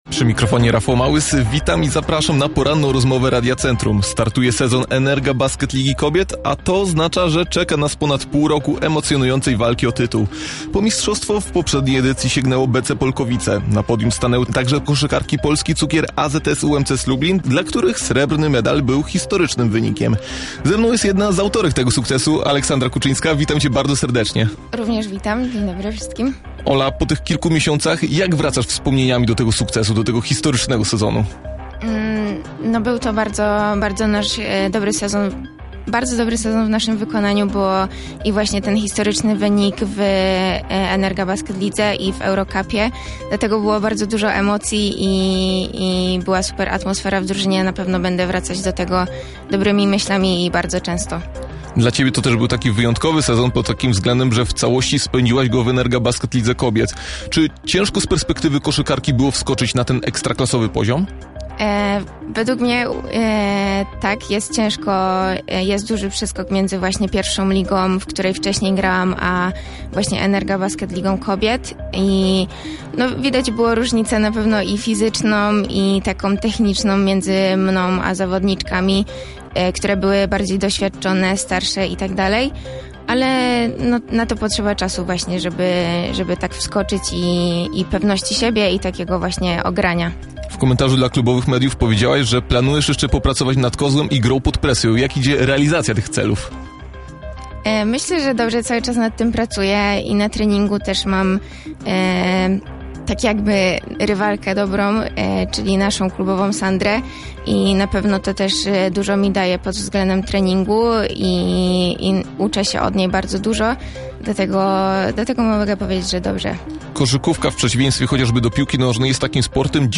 Rozmowa-po-edycji.mp3